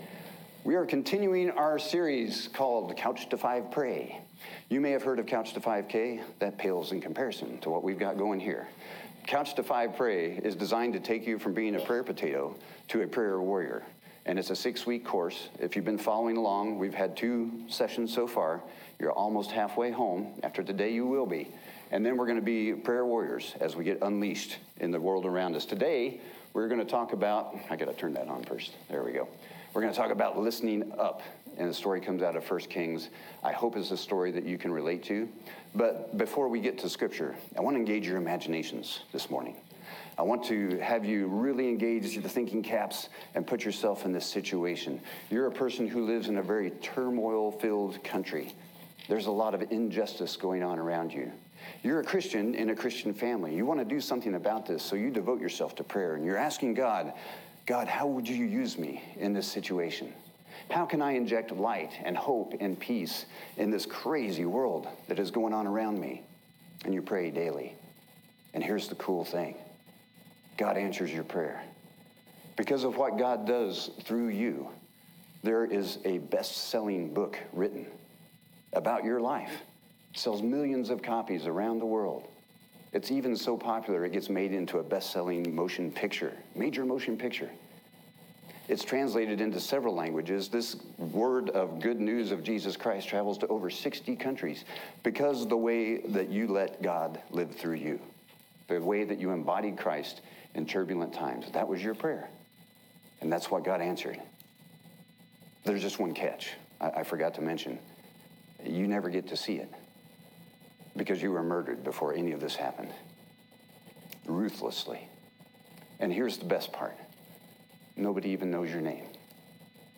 Sermons
audio-sermon-listen-up.m4a